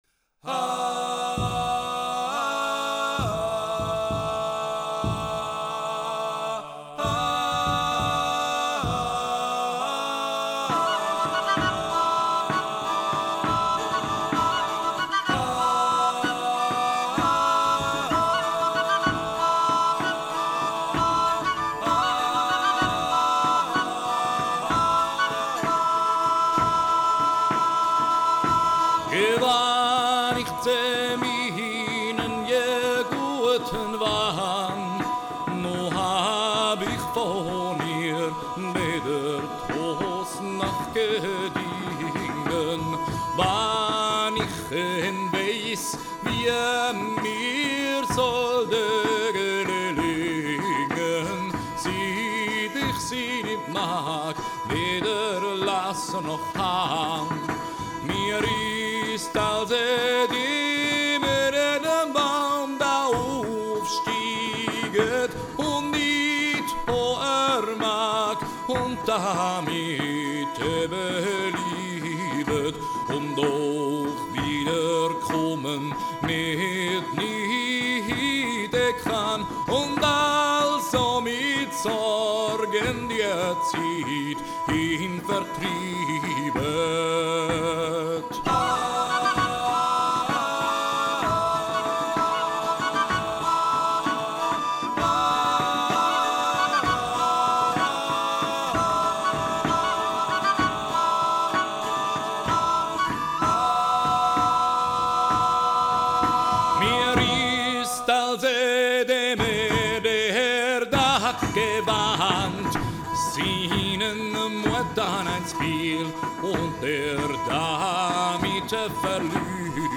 Minnelieder aus dem Hochmittelalter